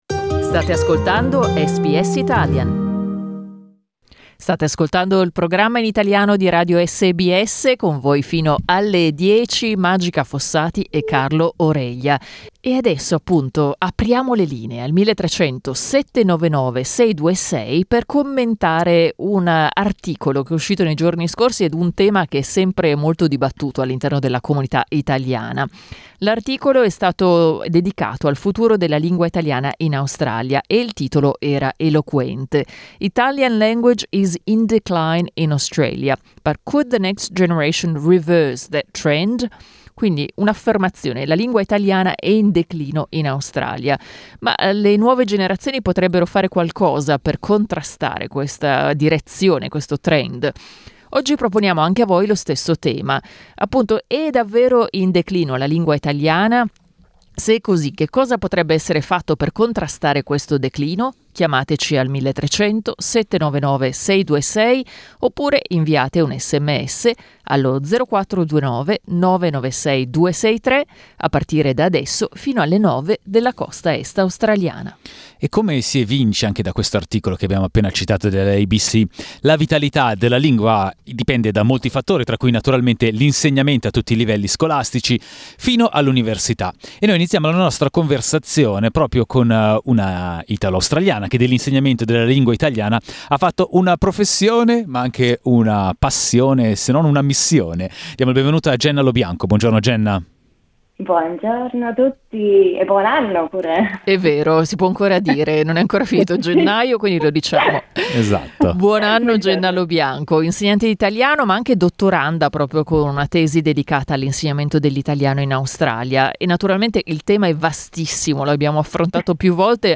La lingua italiana e il suo insegnamento sono davvero in declino? E se è così, cosa potrebbe esser fatto per contrastare questo calo di interesse? Ne abbiamo parlato con alcune insegnanti di italiano in Australia.